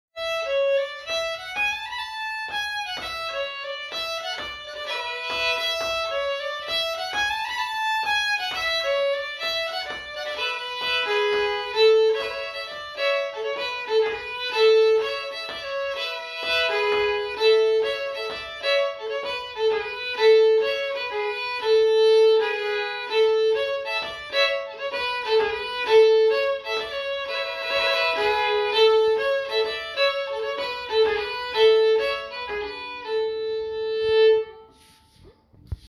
Genre Polska